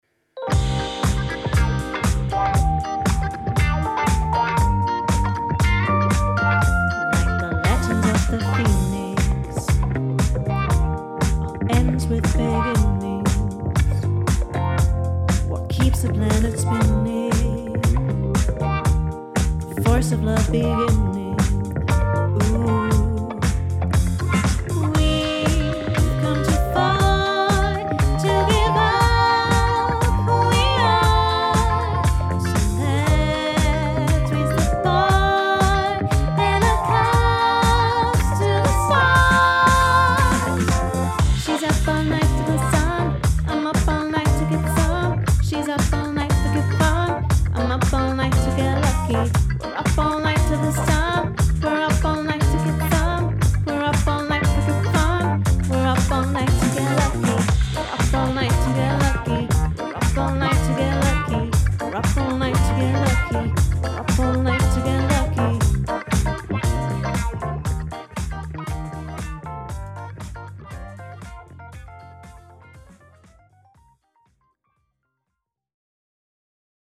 sie klingt sehr pur und klar.